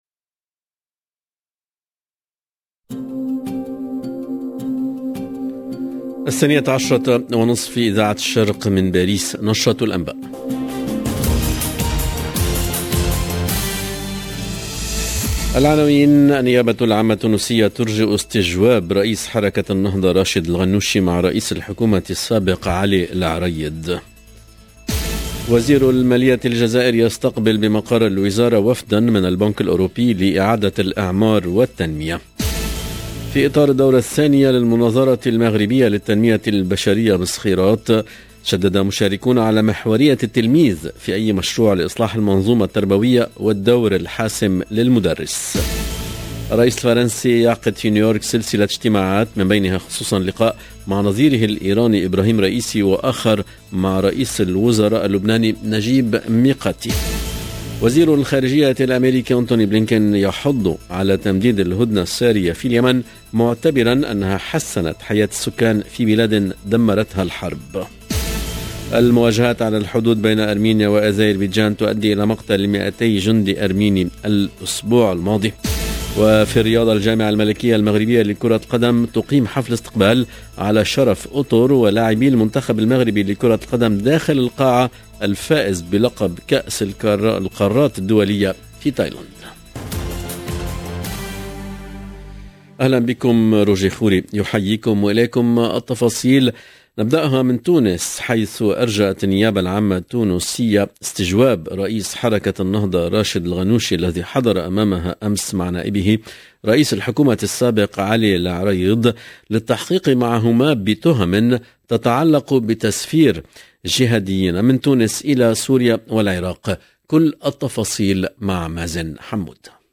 LE JOURNAL EN LANGUE ARABE DE MIDI 30 DU 20/09/22